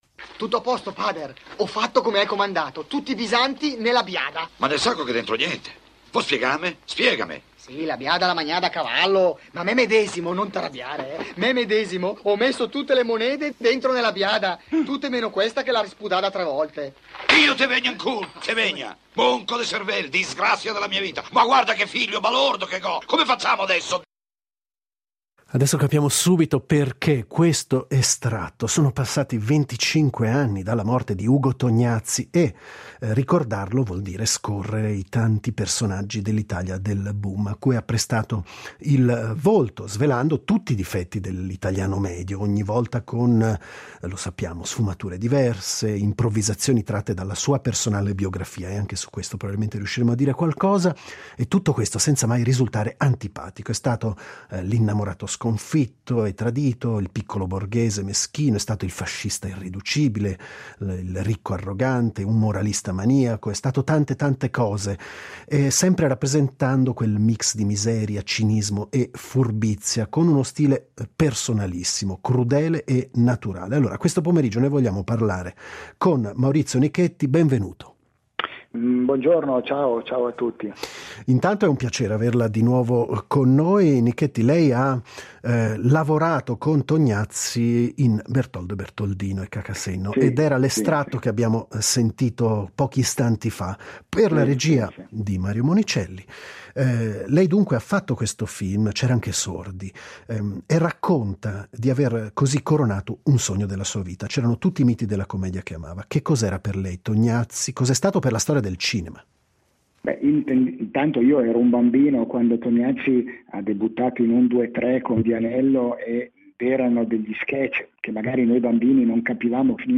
25 anni dalla scomparsa di Ugo Tognazzi. Collegamento con Maurizio Nichetti